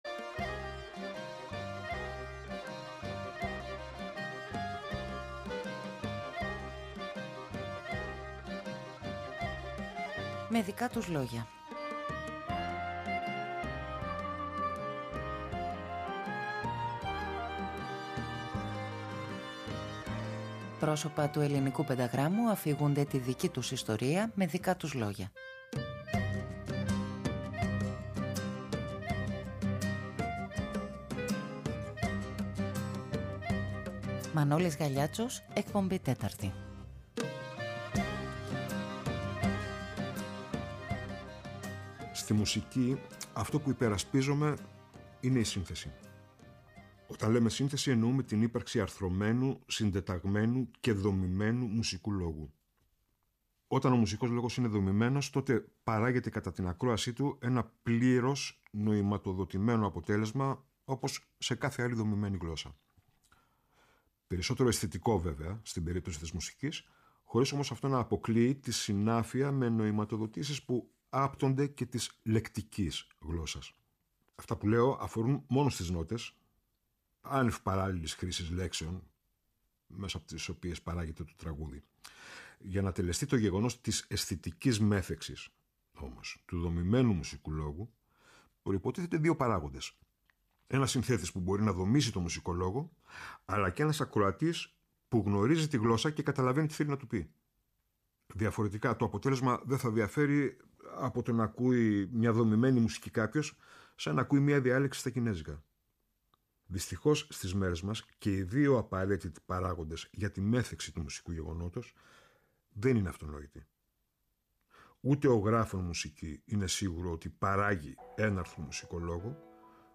Πρόσωπα του πενταγράμμου αφηγούνται τη δική τους ιστορία…
ΔΕΥΤΕΡΟ ΠΡΟΓΡΑΜΜΑ Με Δικα τους Λογια Αφιερώματα Μουσική Συνεντεύξεις